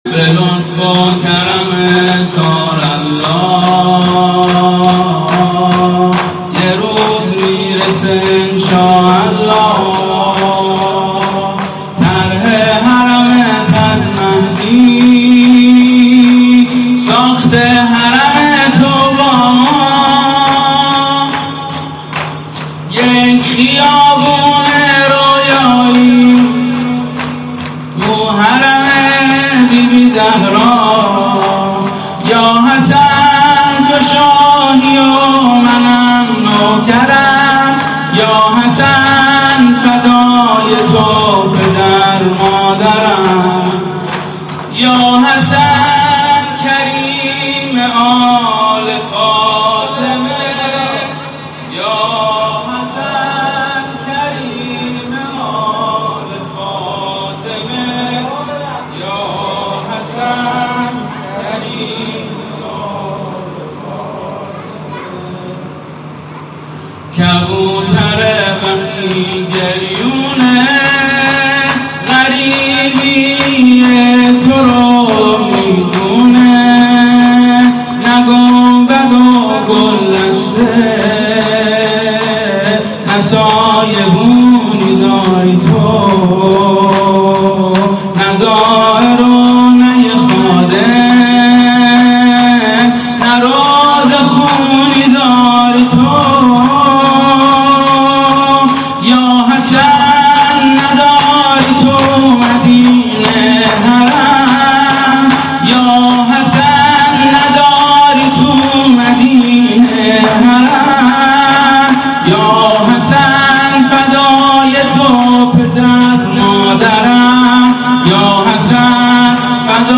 شور به لطف و کرم ثارالله شب پنجم محرم 1442 هیئت ناصرالحسین